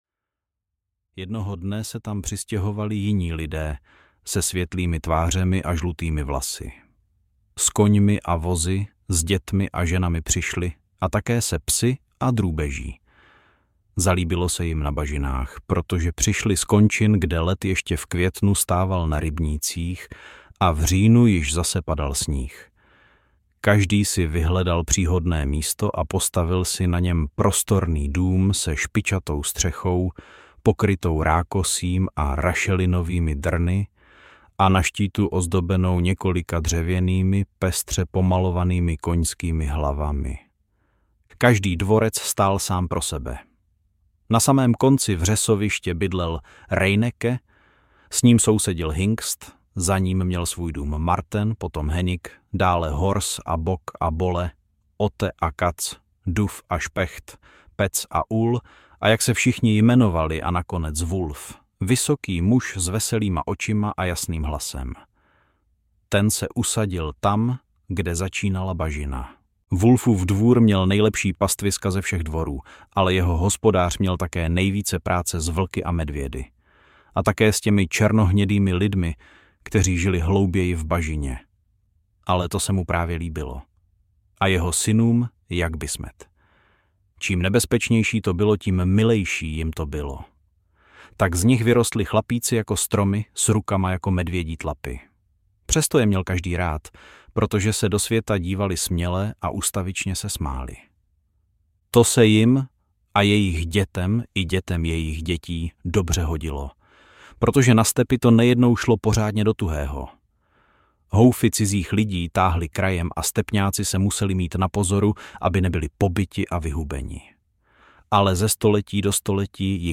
Vlkodlak audiokniha
Ukázka z knihy